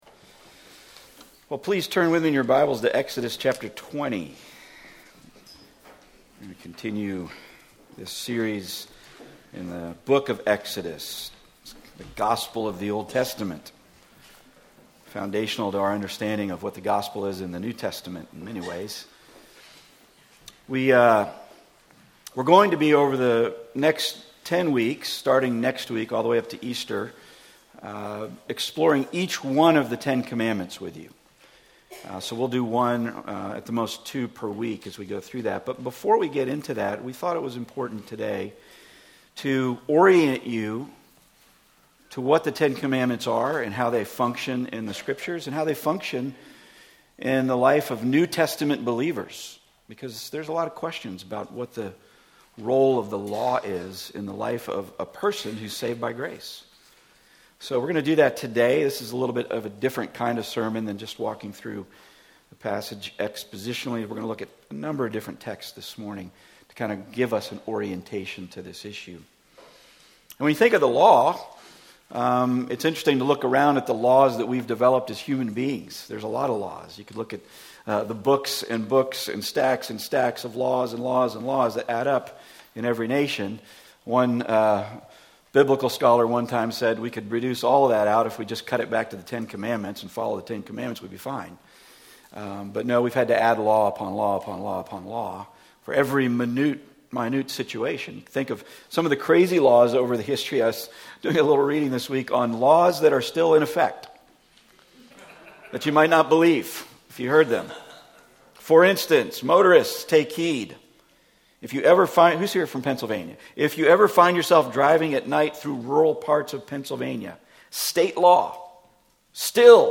Matthew 5:17-20 Service Type: Weekly Sunday